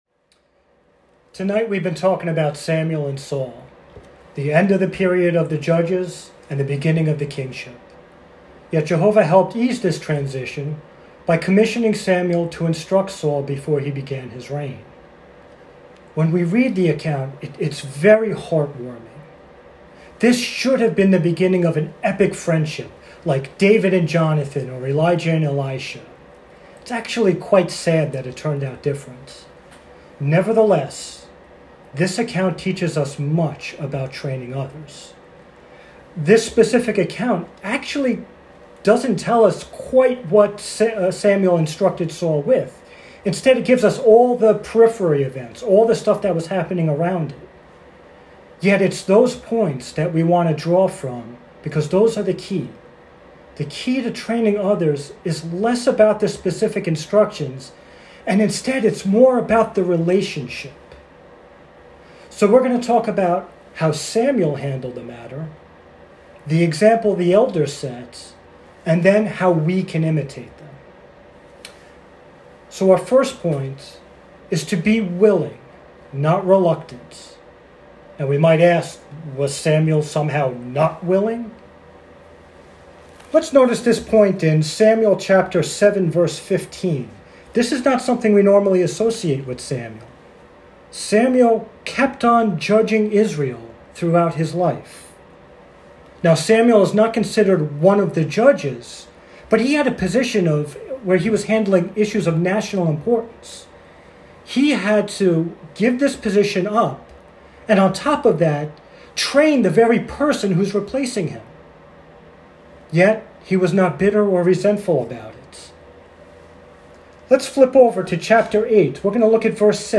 Given at home over Zoom during the Covid years